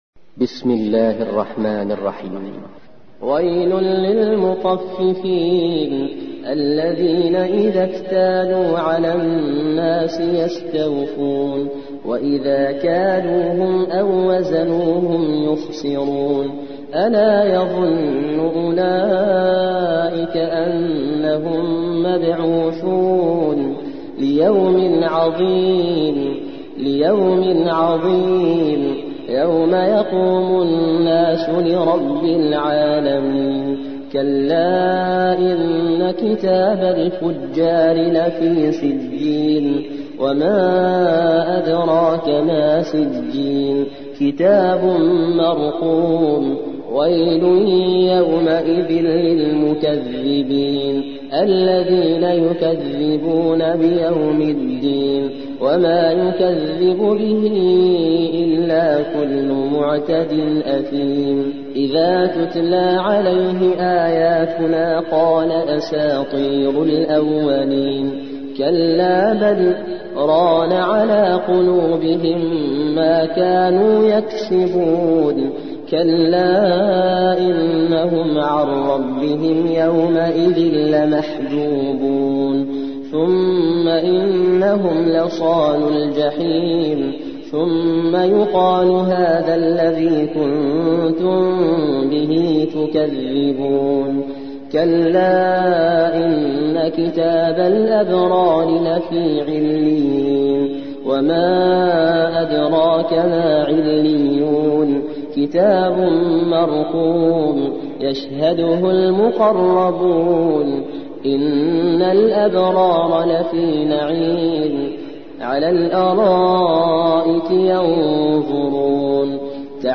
83. سورة المطففين / القارئ